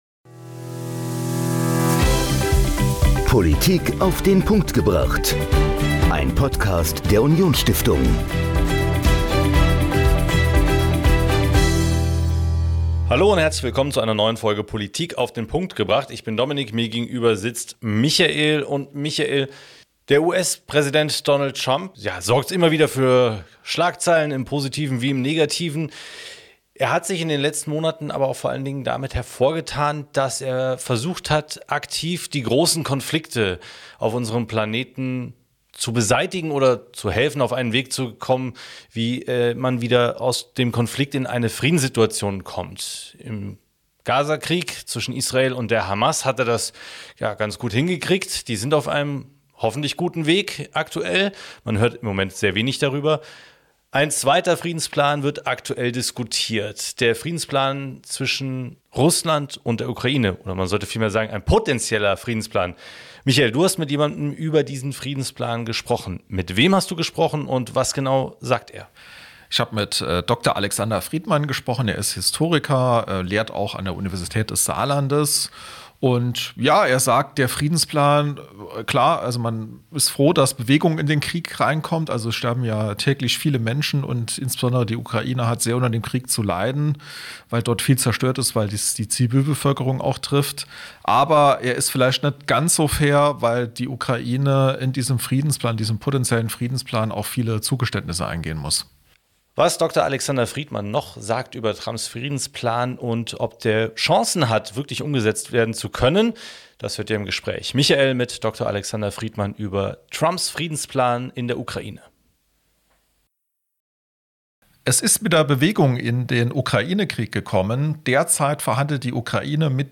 Historiker und Ost-Europaexperte spricht in dieser Folge über den Friedensplan, den Donald Trump für die Ukraine vorgestellt hat.